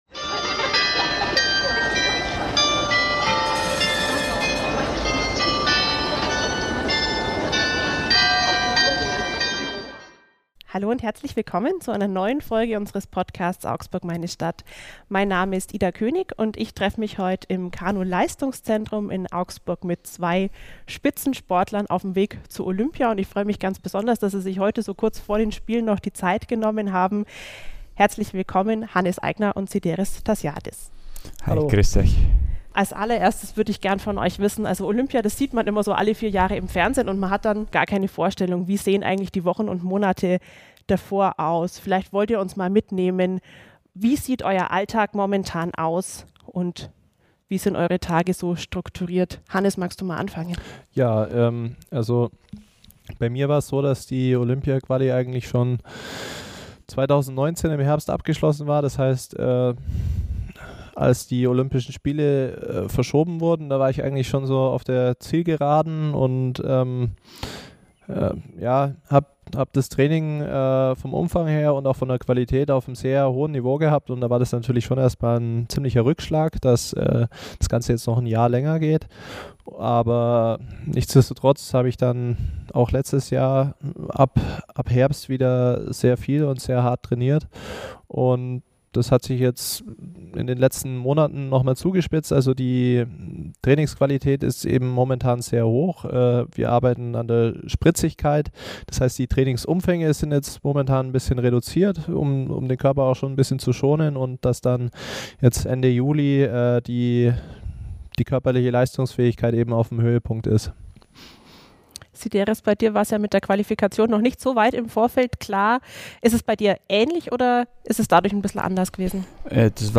Kurz vor ihrer Abreise nach Japan waren sie zu Gast im Podcast "Augsburg, meine Stadt" und gaben Einblicke in den Alltag von Spitzensportlern. Im Gespräch erklären sie, wie eine Vorbereitung unmittelbar vor Olympia aussieht und welche Auswirkungen es für sie hatte, dass die Spiele wegen der Pandemie um ein Jahr verschoben wurden. Außerdem sprechen sie darüber, worauf sie in Tokio aufgrund von Corona verzichten müssen und welche speziellen Regeln die Athleten vor Ort einzuhalten haben.